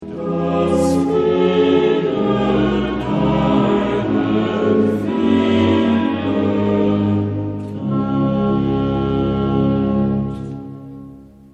Bach koralen
Op de eerste tel: IV. Er is hier sprake van een chromatische modulatie.
Een volkomen heel slot.